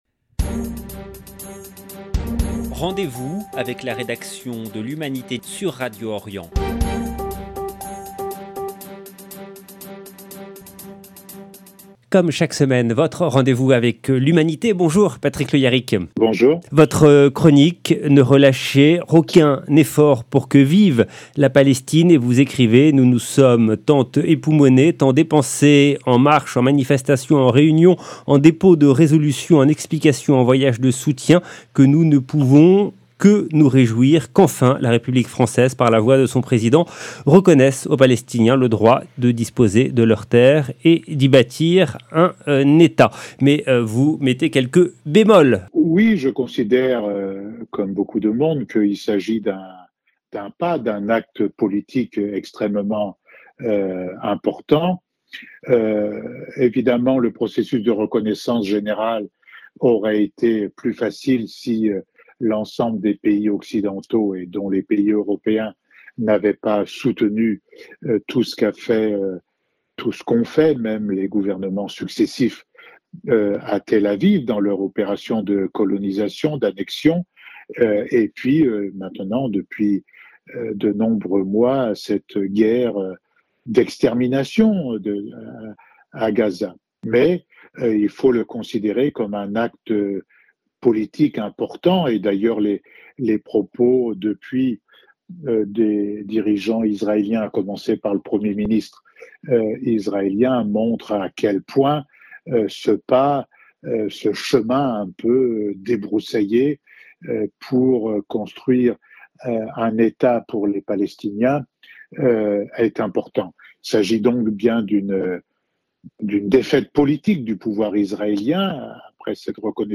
Chronique de l'Humanité du 25 septembre 2025